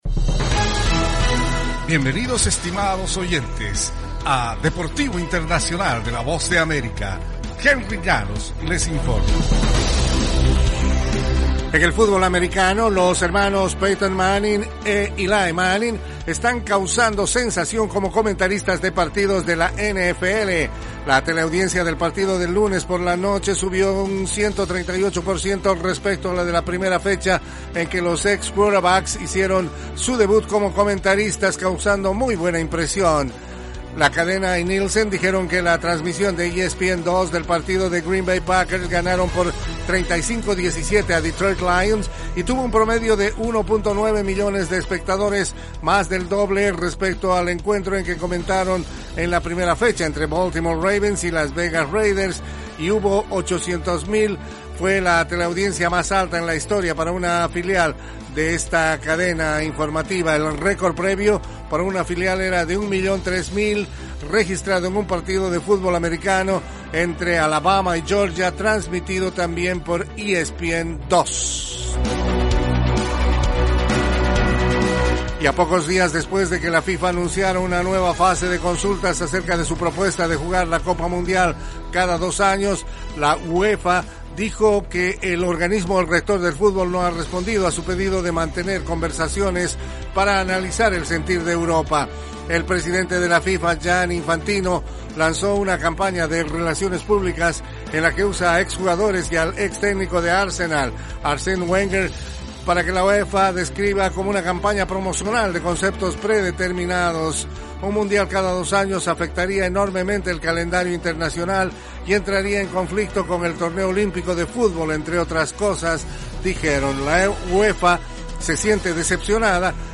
Deportivo Internacional - Las noticias deportivas llegan desde los estudios de la Voz de América